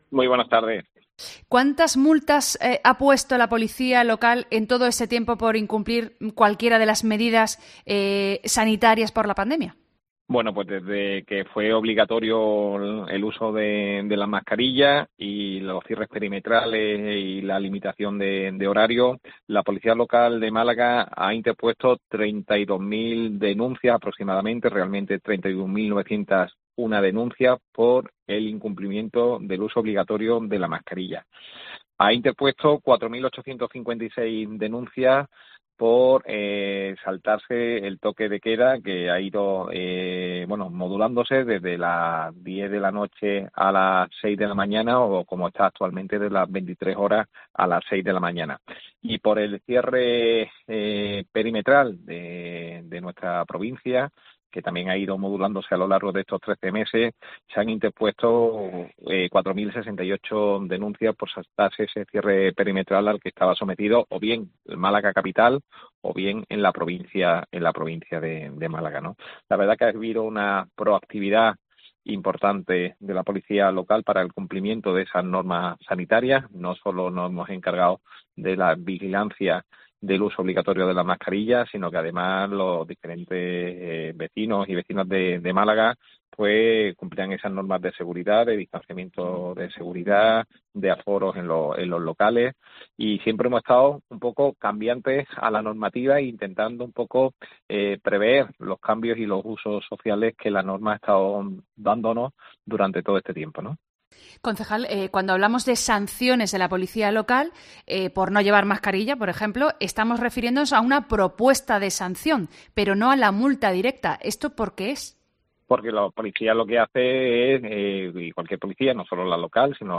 El concejal de Seguridad del Ayuntamiento de Málaga, Avelino Barrionuevo, en COPE Málaga